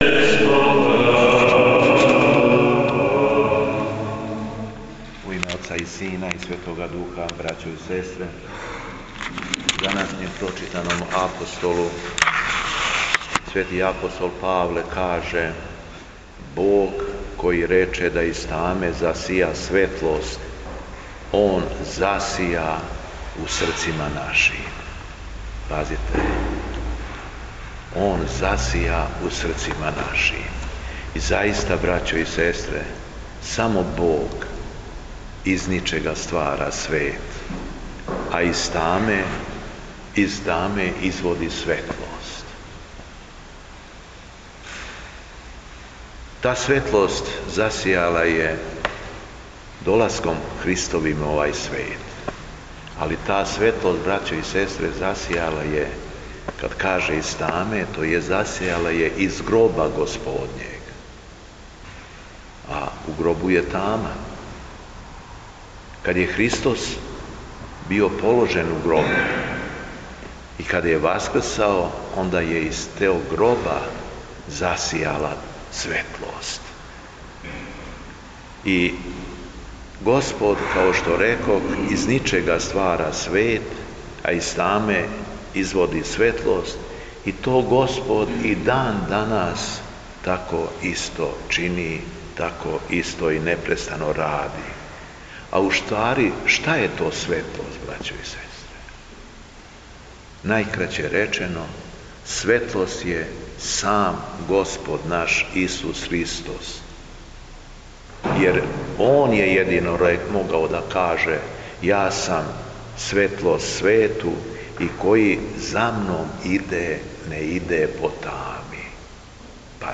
Беседа Његовог Високопреосвештенства Митрополита шумадијског г. Јована
После прочитаног јеванђелског зачала, Високопреосвећени Митрополит се обратио беседом сабраном народу: